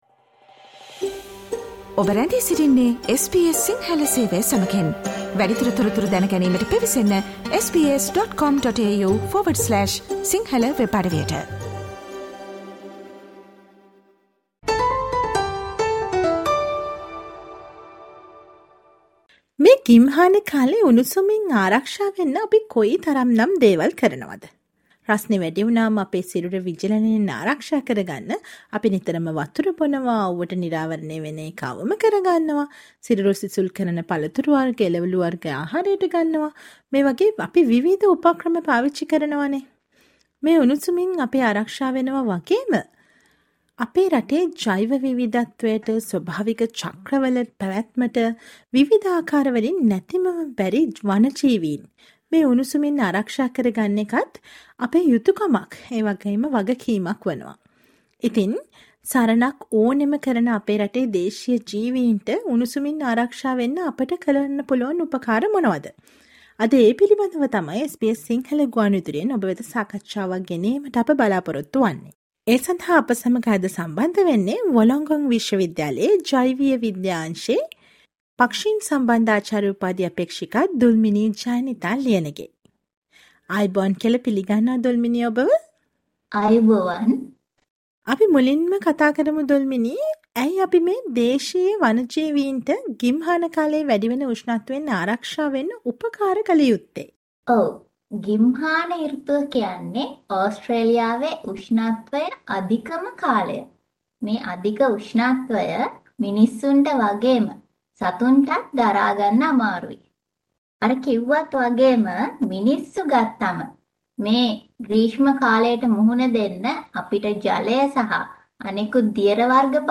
Listen to the SBS Sinhala interview to learn how to identify and help heat stressed native animals in Australia this summer.